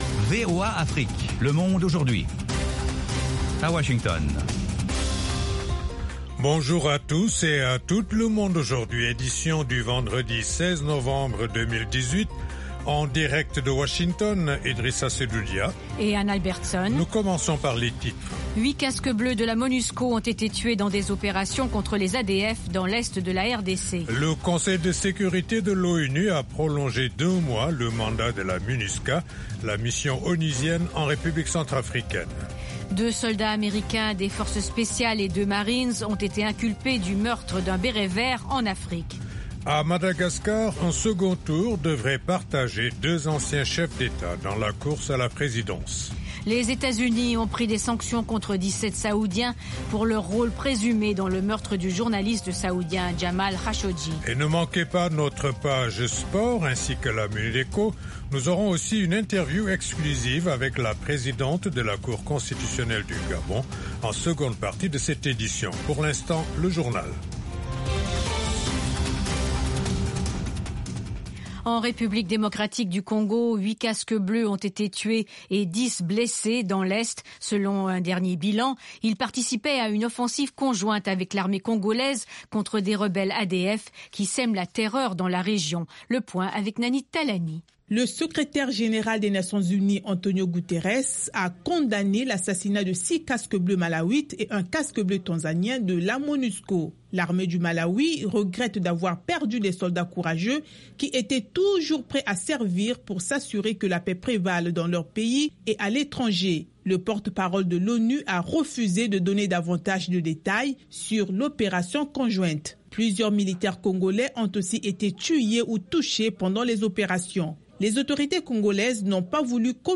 Toute l’actualité sous-régionale sous la forme de reportages et d’interviews.